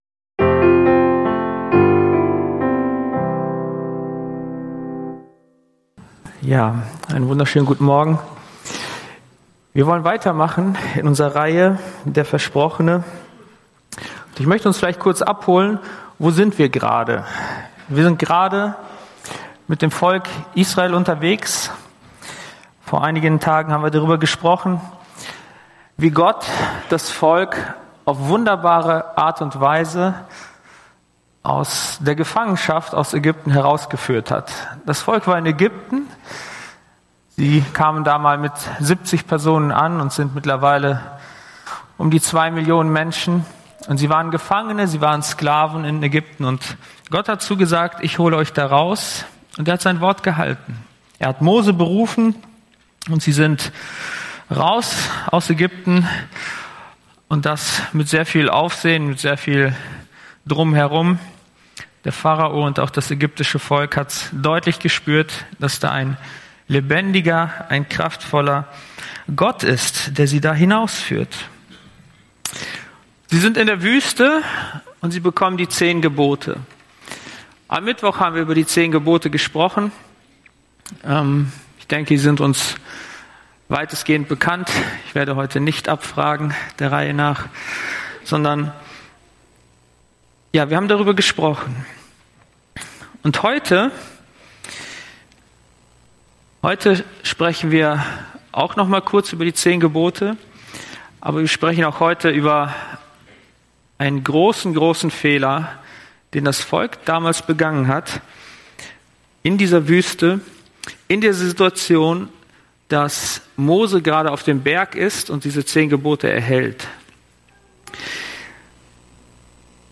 Hauptpredigt